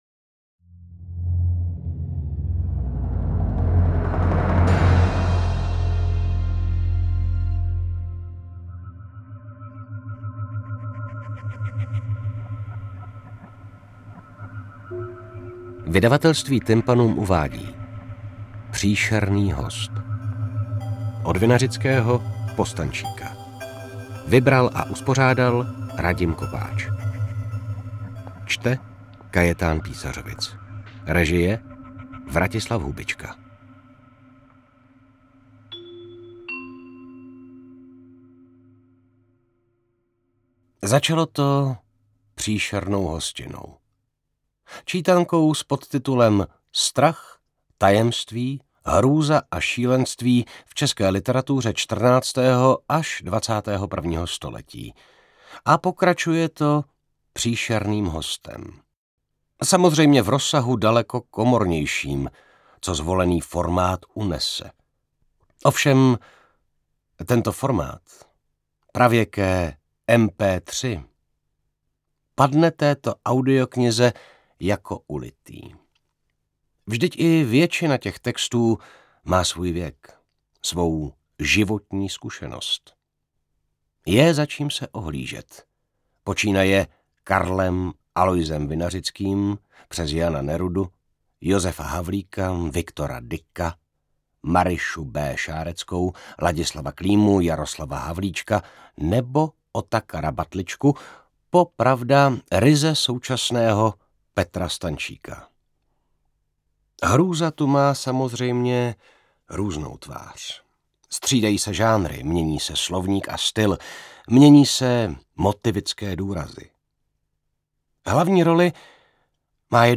Ovšem ten nosič, pravěká kazeta, padne téhle audio-knize jako...